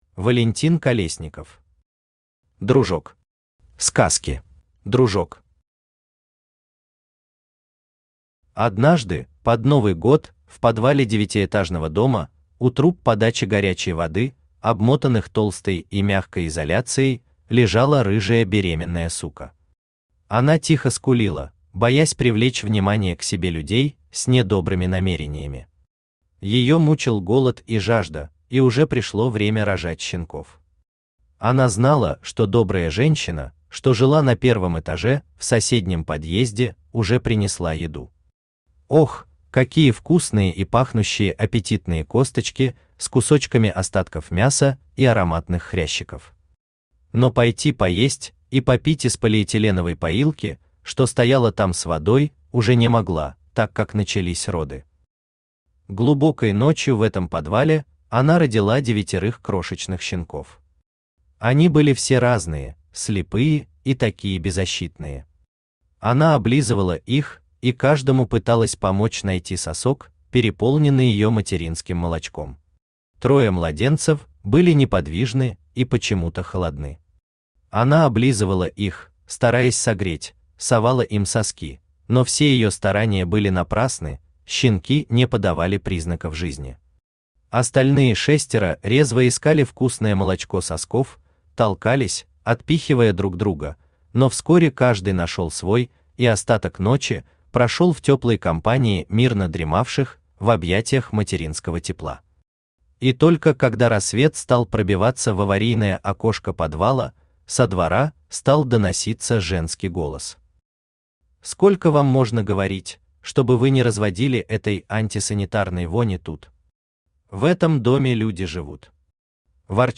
Аудиокнига Дружок. Сказки | Библиотека аудиокниг
Сказки Автор Валентин Колесников Читает аудиокнигу Авточтец ЛитРес.